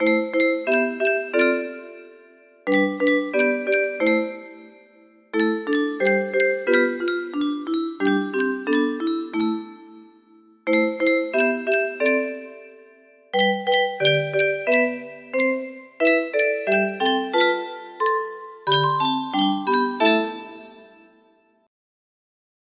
Fully playable on the 30-note music box.